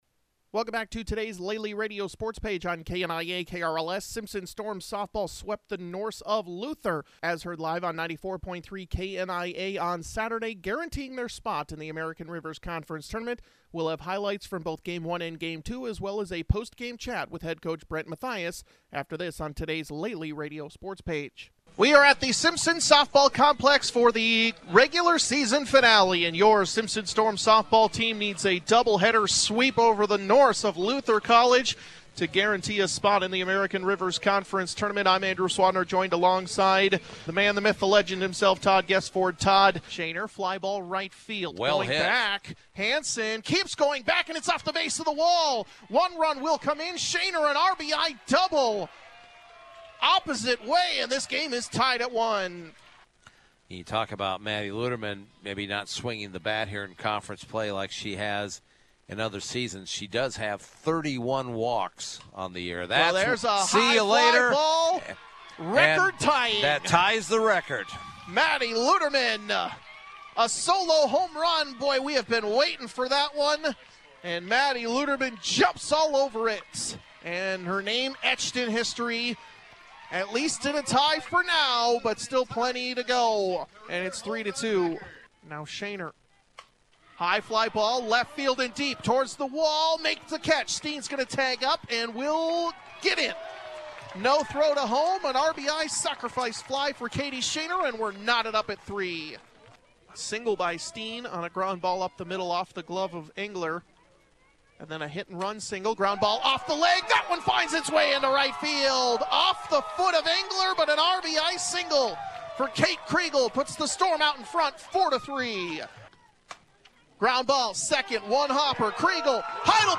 highlights and an interview